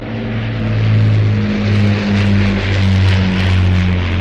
The unmistakable sound of four Rolls Royce Merlin engines.